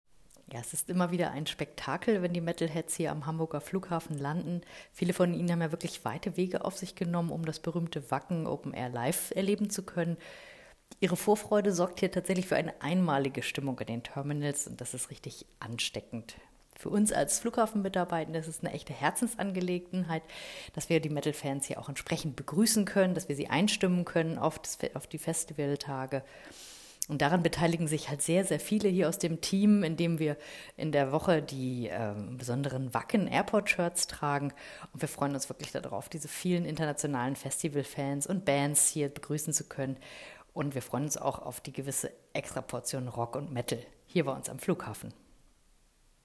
Audio Statement